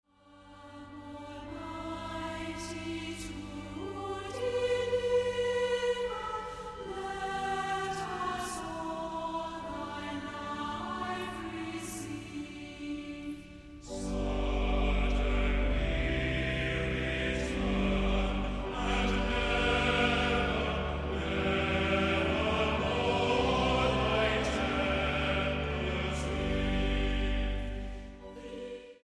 STYLE: Hymnody